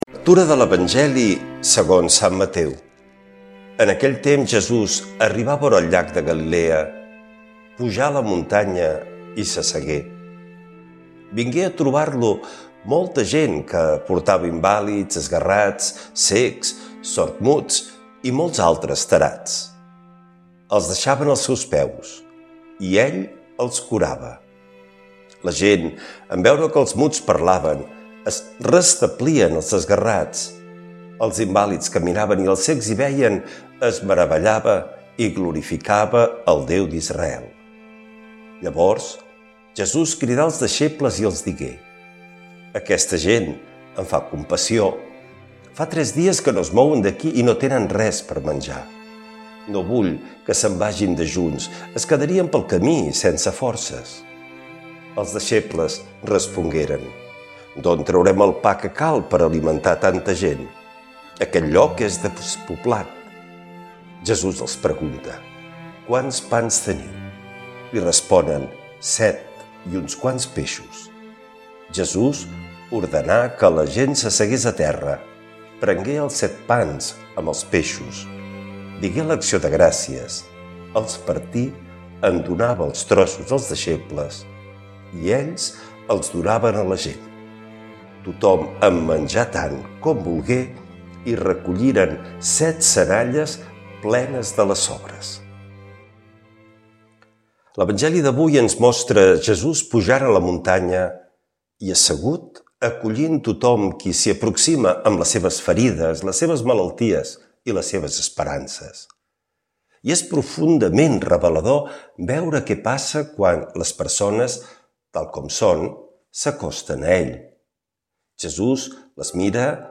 L’Evangeli i el comentari de dimecres 03 de desembre del 2025.
Lectura de l’evangeli segons sant Mateu